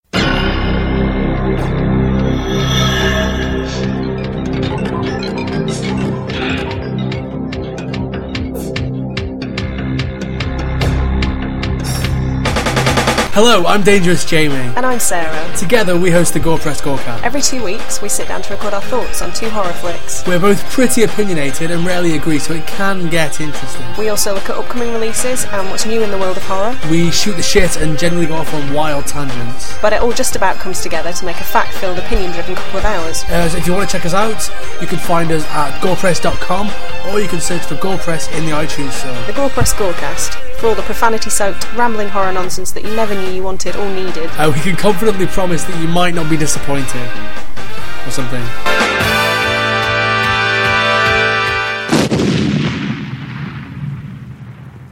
We know you were bored of just reading our words, so now hear our voices.